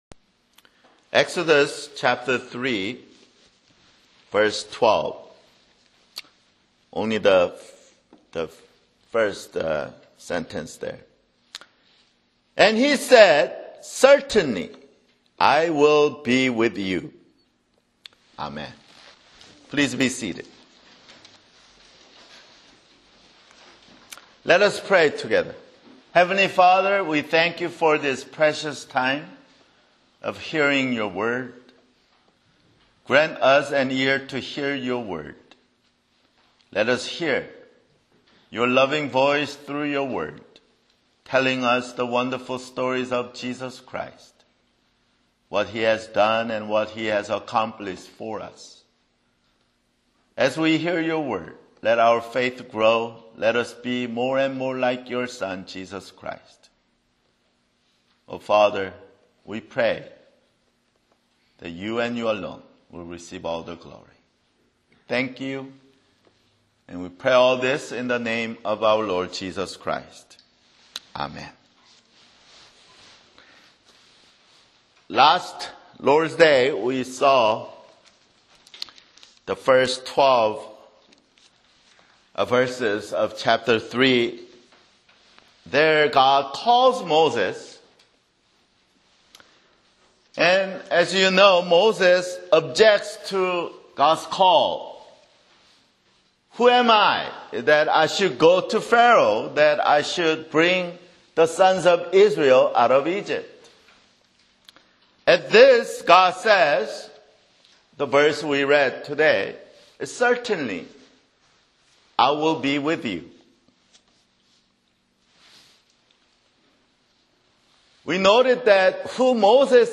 [Sermon] Exodus (6)